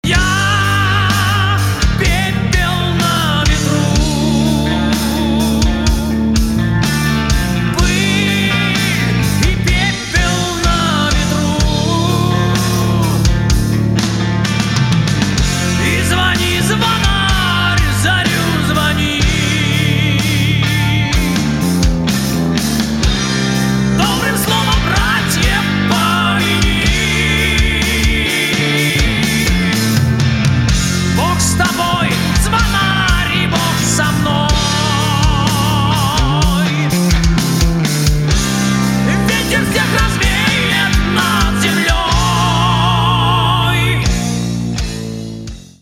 • Качество: 192, Stereo
спокойные
Metal
рок